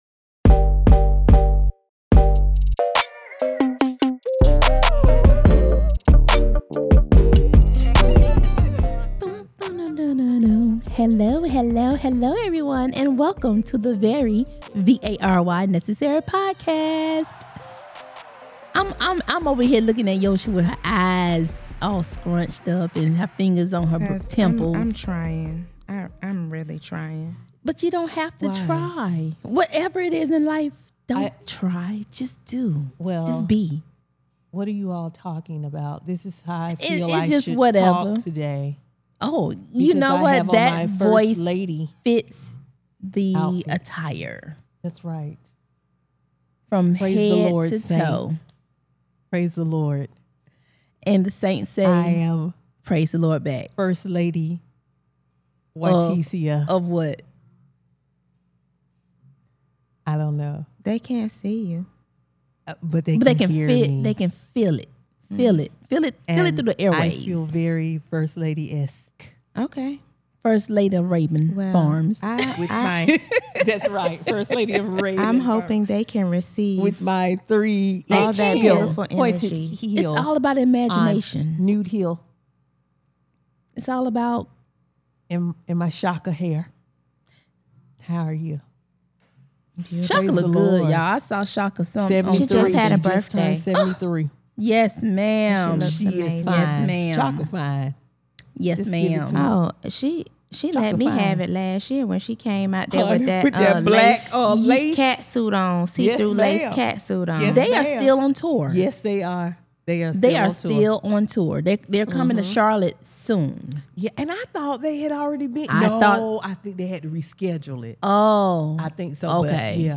The ladies are back and the conversation is moving fast!